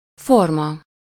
Ääntäminen
Synonyymit shape genre constitution Ääntäminen France: IPA: [fɔʁm] Haettu sana löytyi näillä lähdekielillä: ranska Käännös Ääninäyte 1. alak 2. forma Suku: f .